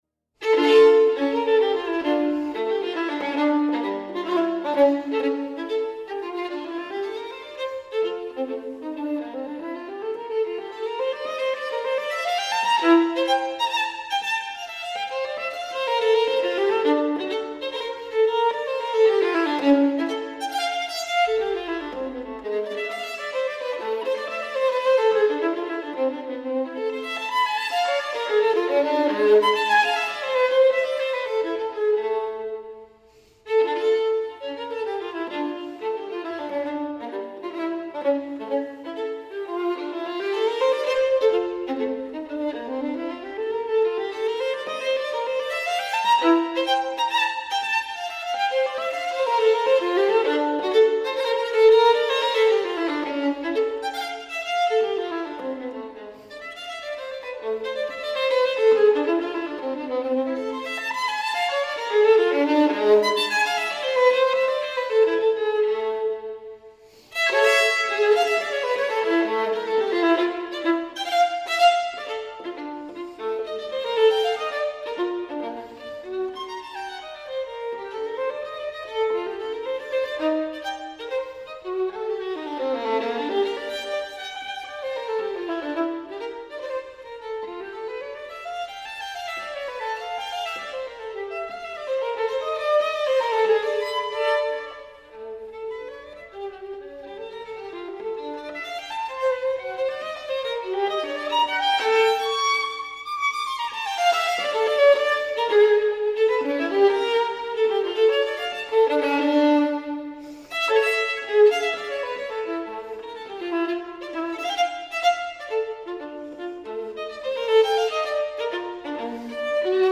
Incontro con il mastro liutaio